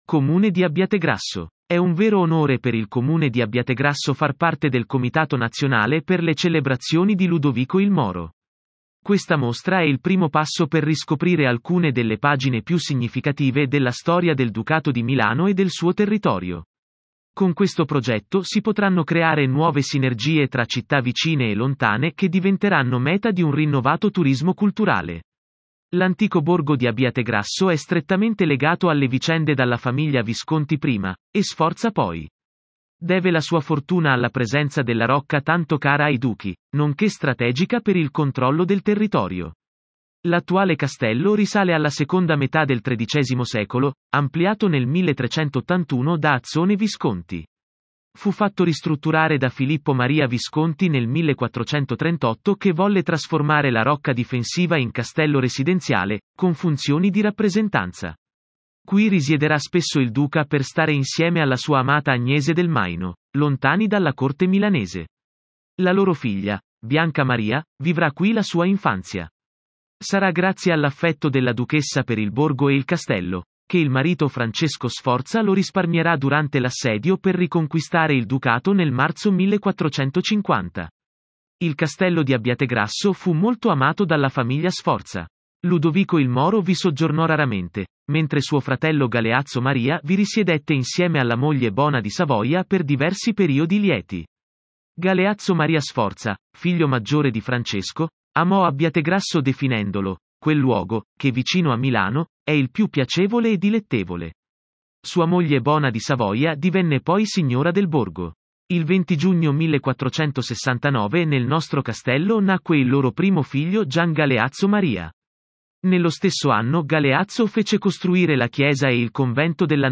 • Audioguida Città di Abbiategrasso